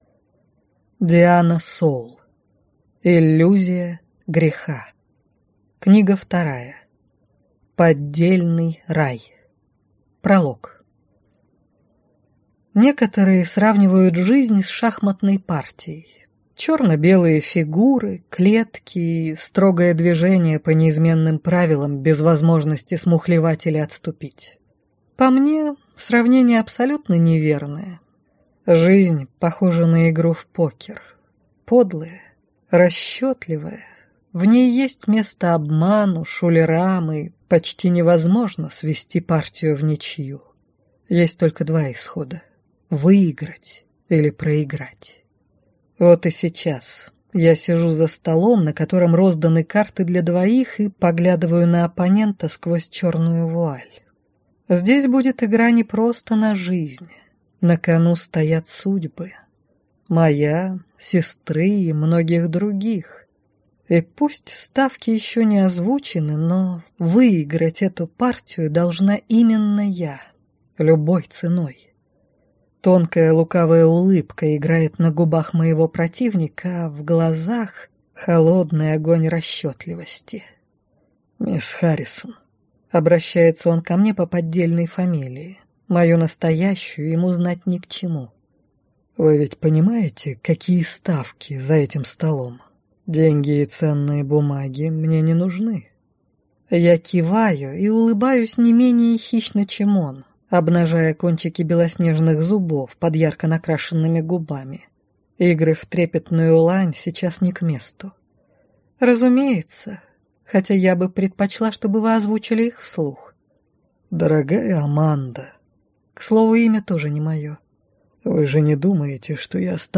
Аудиокнига Иллюзия греха. Поддельный Рай | Библиотека аудиокниг
Прослушать и бесплатно скачать фрагмент аудиокниги